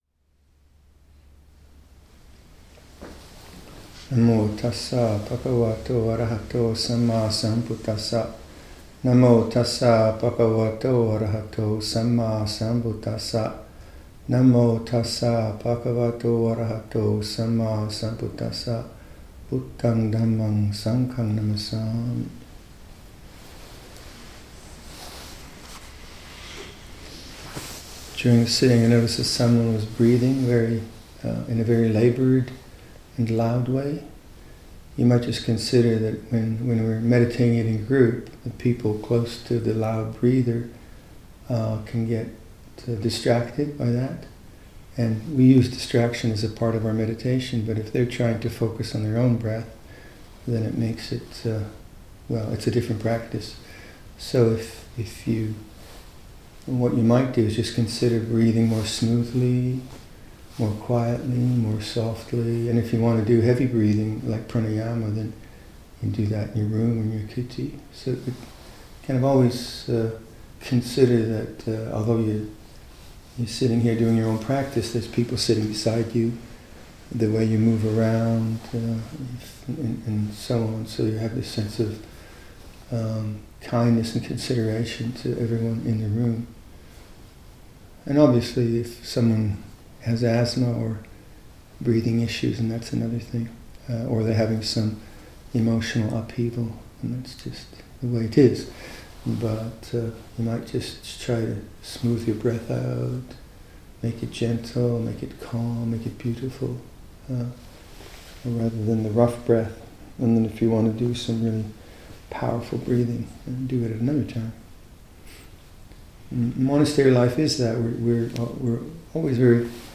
Tisarana Dhamma Talks, 2018